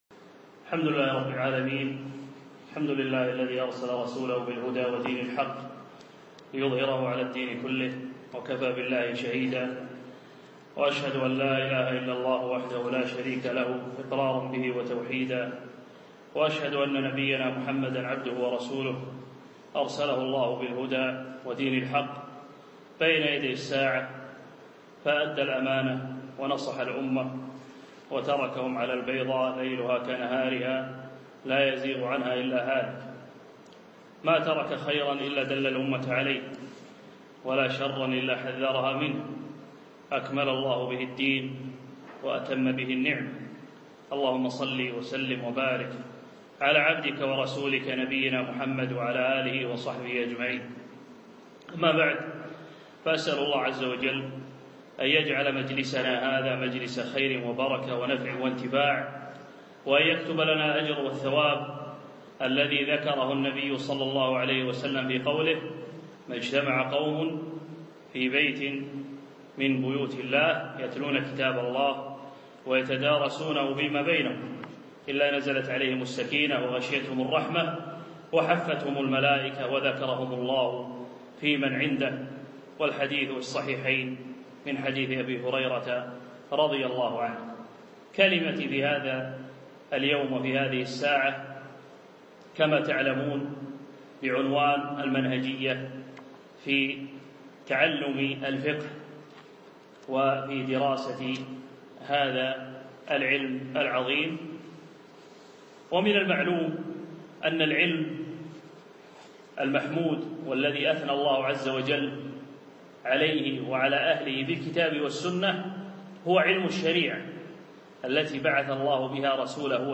أقيمت المحاضرة في مركزالفردوس نساء